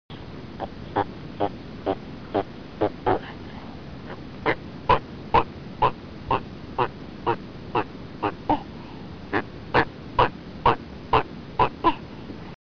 Звуки красноногой черепахи